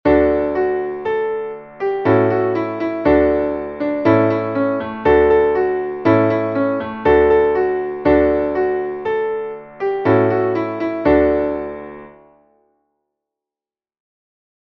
Traditionelles Lied